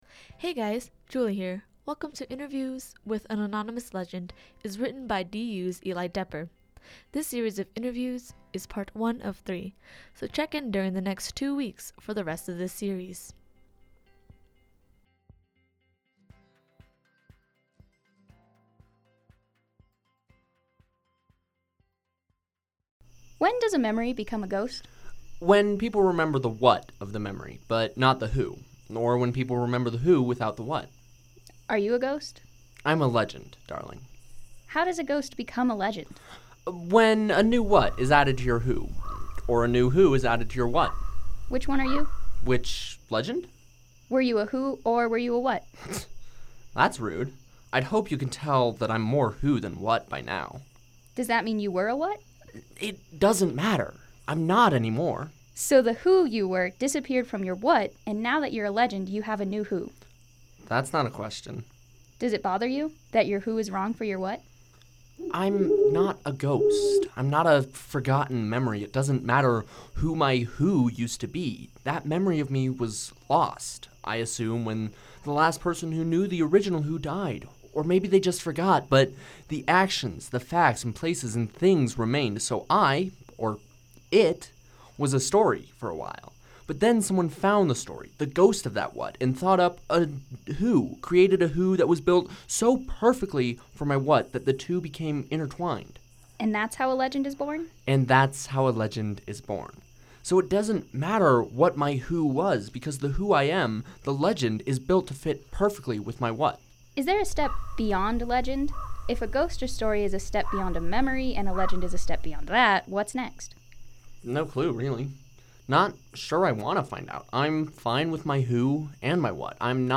• Interviewer character
• Legend character
• Sound effects - owl hoot